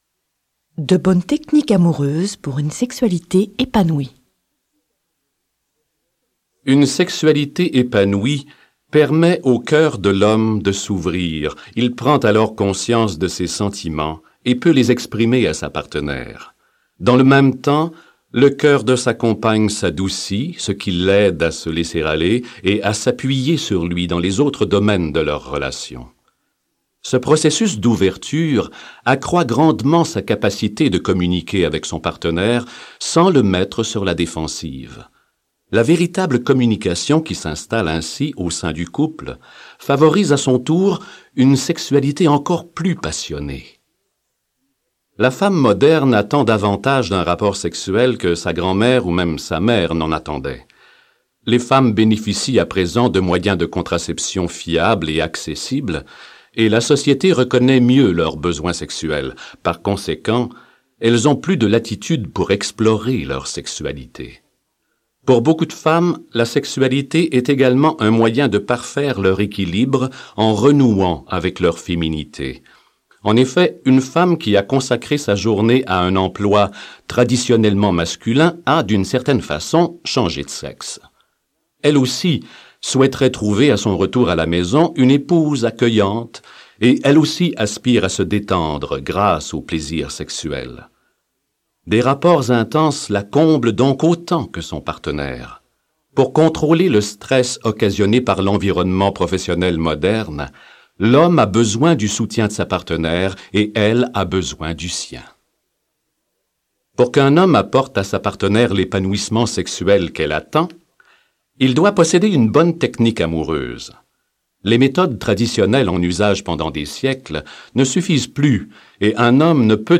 Click for an excerpt - Mars et Vénus sous la couette de John Gray